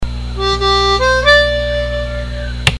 2stepbend.mp3